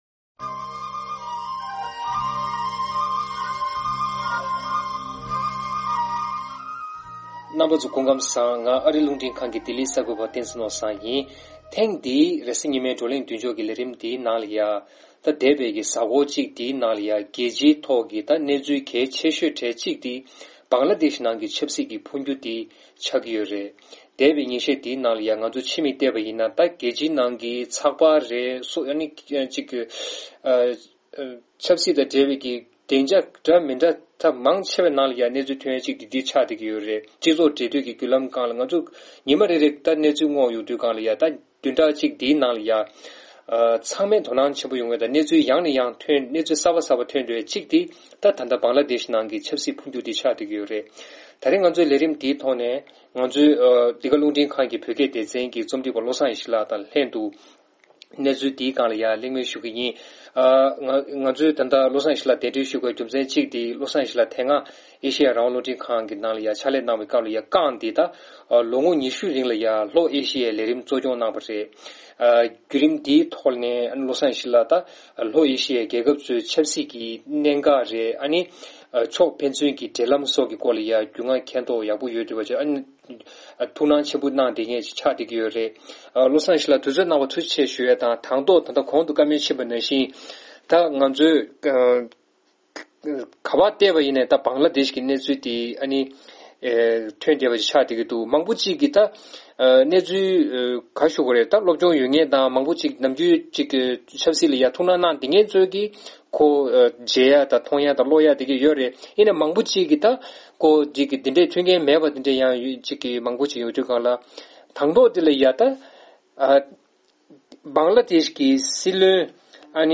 གླེང་མོལ་ཞུས་ཡོད།